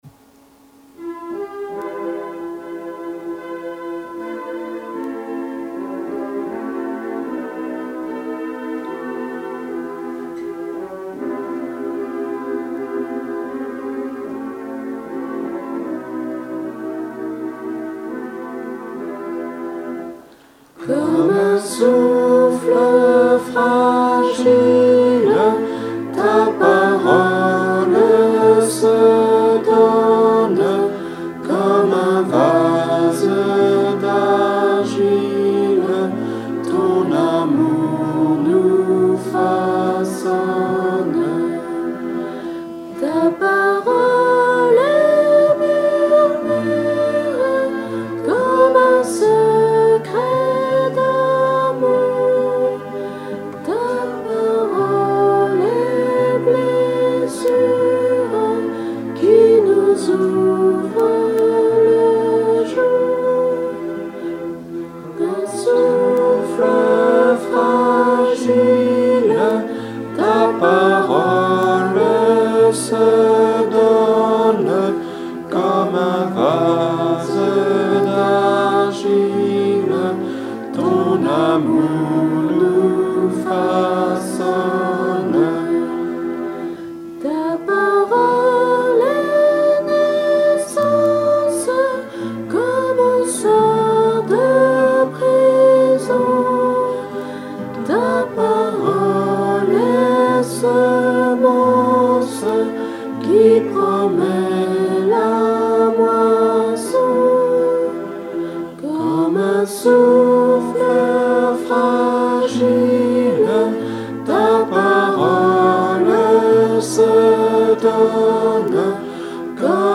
[ Messe de mariage ]